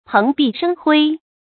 蓬蓽生輝 注音： ㄆㄥˊ ㄅㄧˋ ㄕㄥ ㄏㄨㄟ 讀音讀法： 意思解釋： 篷篳：用草、荊條等編成的門戶；借以代簡陋的房屋。